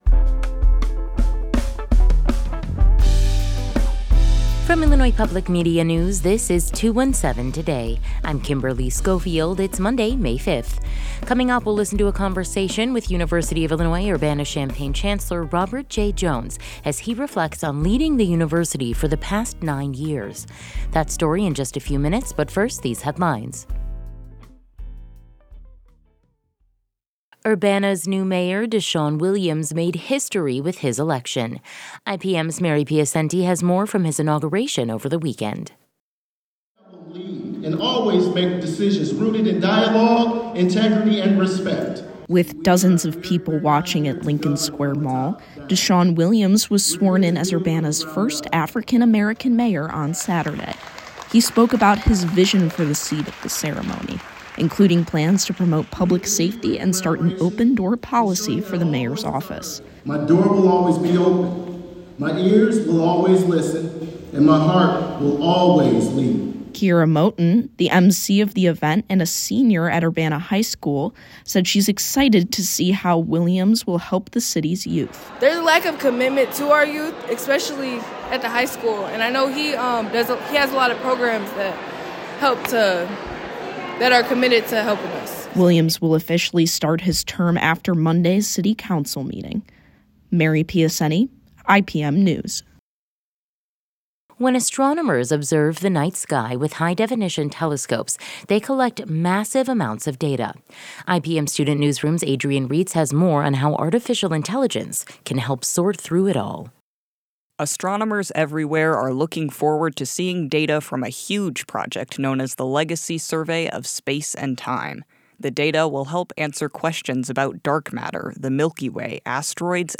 In today's deep dive, we’ll listen to a conversation with University of Illinois Urbana-Champaign Chancellor Robert J. Jones as he reflects on leading the university for the past nine years.